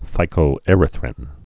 (fīkō-ĕrĭ-thrĭn)